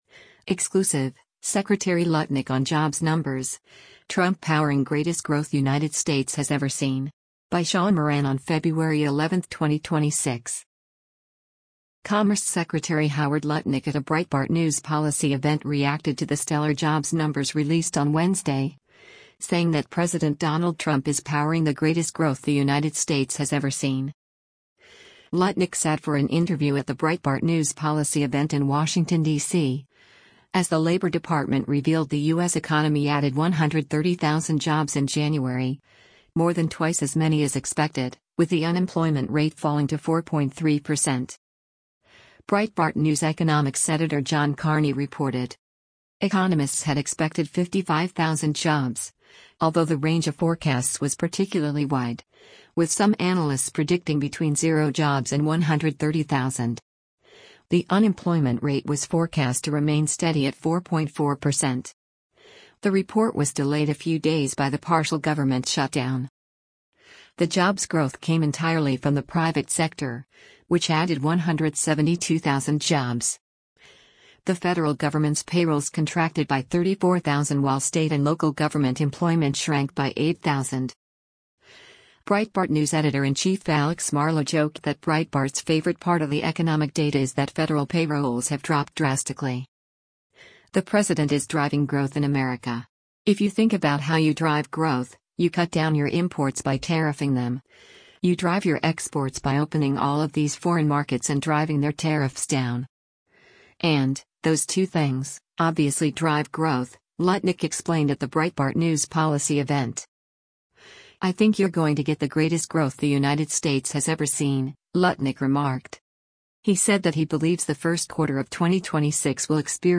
Lutnick sat for an interview at the Breitbart News policy event in Washington, DC, as the Labor Department revealed the U.S. economy added 130,000 jobs in January, more than twice as many as expected, with the unemployment rate falling to 4.3 percent.